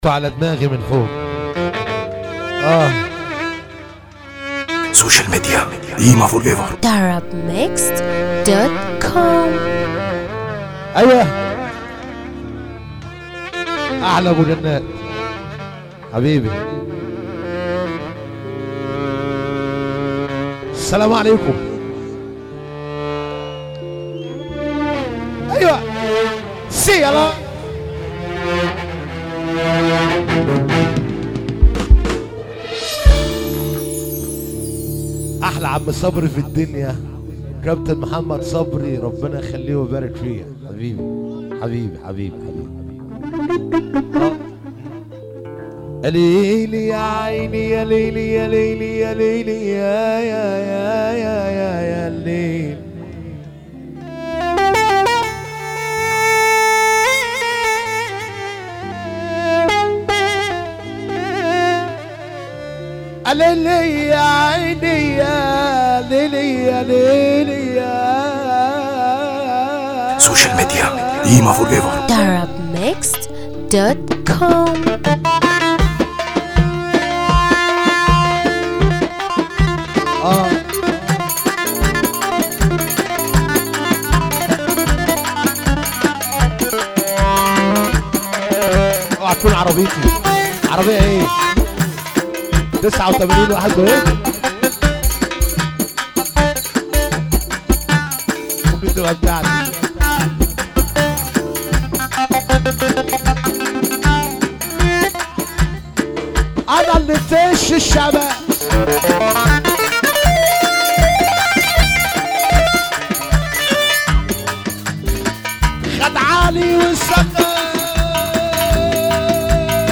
موال
باحساس عالى